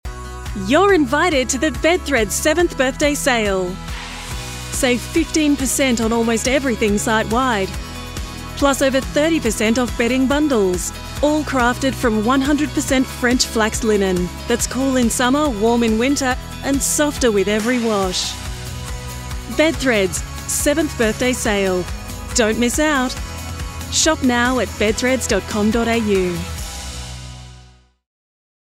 Female
Television Spots
Words that describe my voice are Medical narration expert, Warm and comfortable, Authentic Australian.